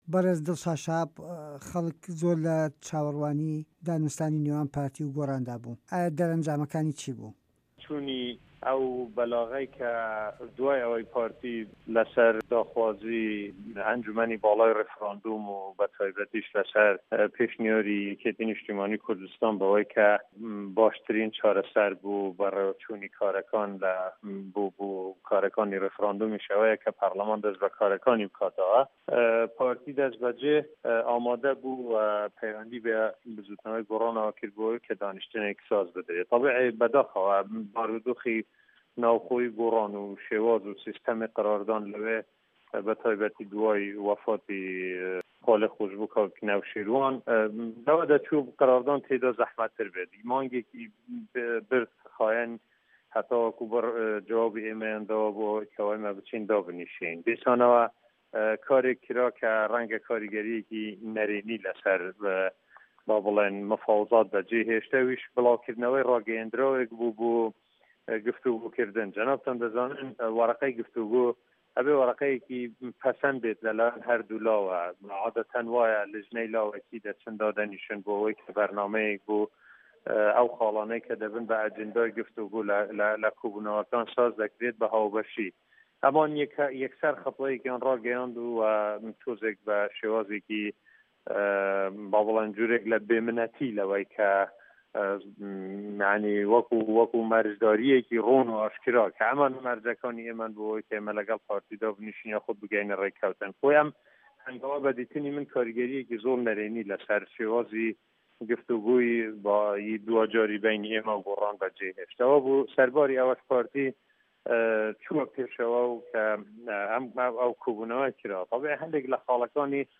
وتووێژ لەگەڵ دڵشاد شەهاب